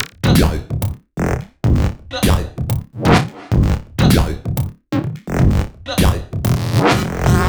Index of /VEE/VEE Electro Loops 128 BPM
VEE Electro Loop 130.wav